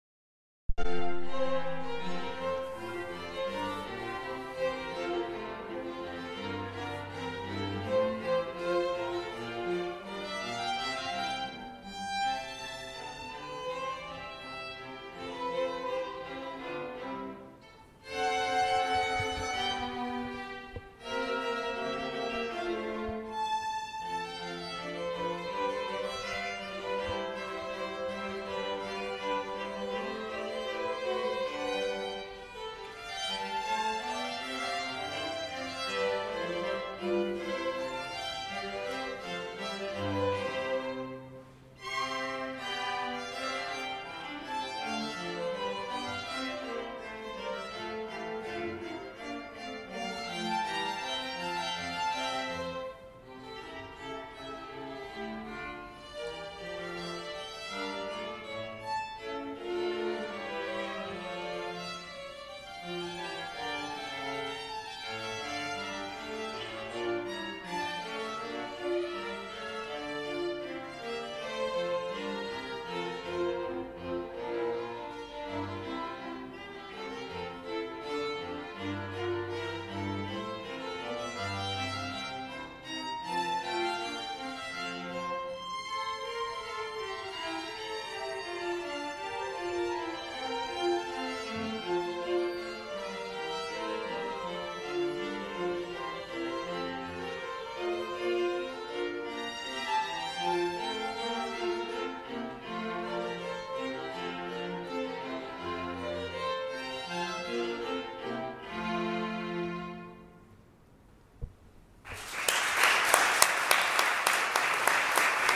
quartet
Strings Concert Feb 2015